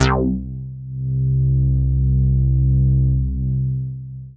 C4_moogy.wav